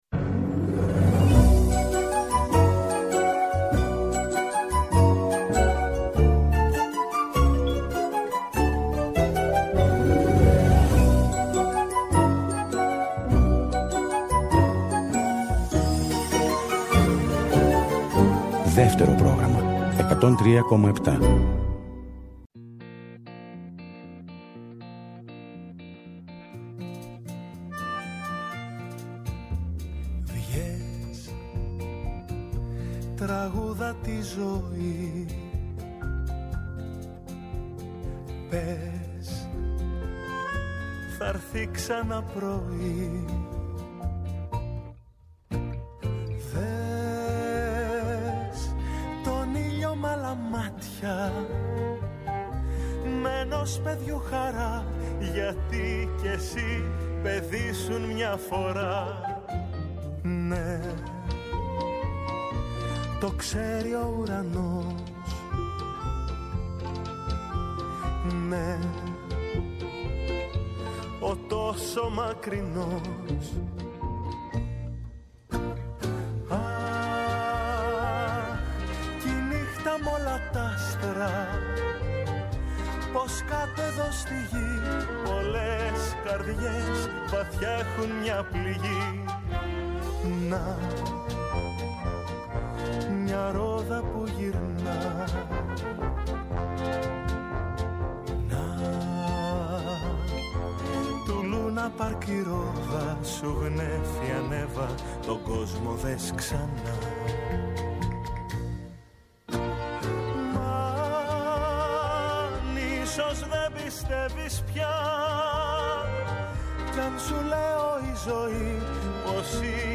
Πέμπτη 22 Δεκεμβρίου 2022 οι μουσικοί σταθμοί της ΕΡΤ εκπέμπουν ζωντανά από την Εθνική Πινακοθήκη.
Συνεντεύξεις